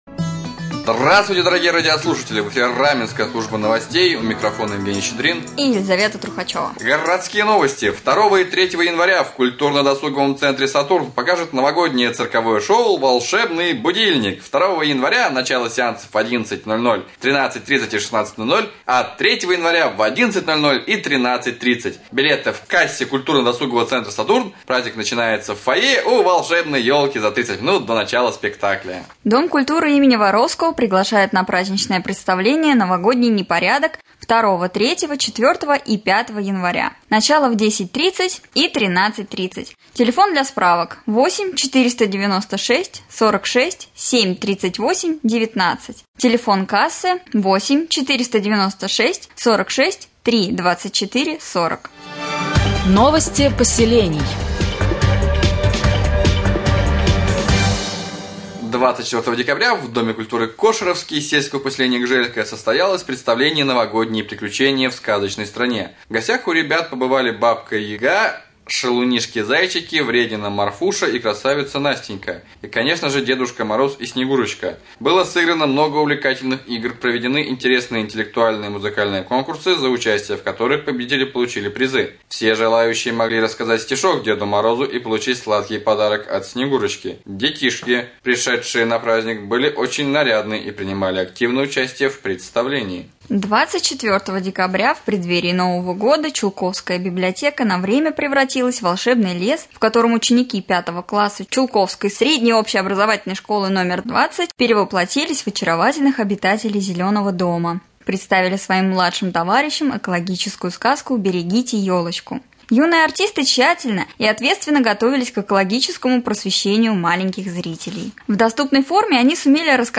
1.Новости